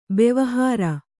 ♪ bevahāra